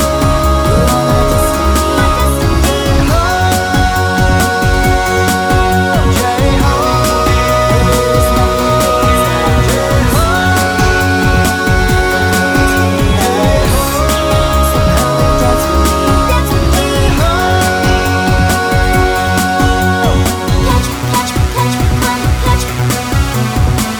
- Duet Version R'n'B / Hip Hop 3:41 Buy £1.50